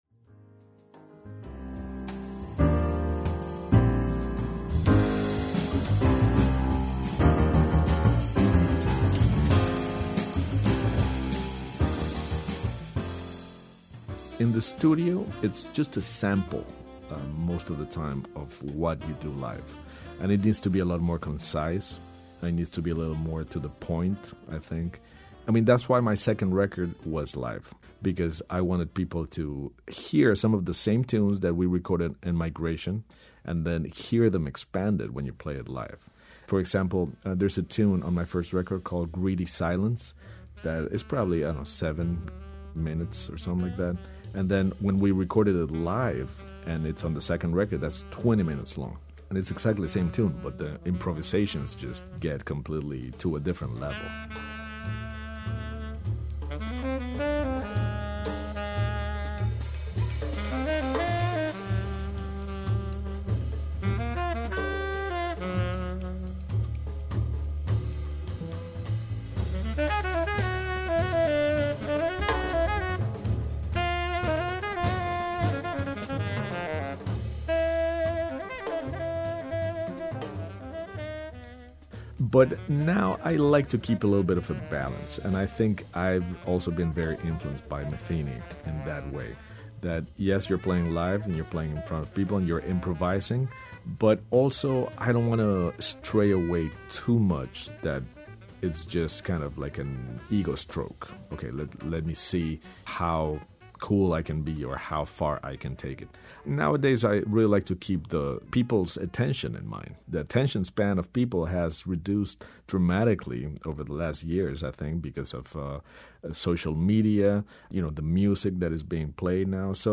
He's also a four-time Grammy winning jazz drummer who has played with some of the greats, including Pat Metheney, Chick Corea, and Paquito D'Rivera. Here's Sanchez talking the difference between playing music in a gig and recording in a studio.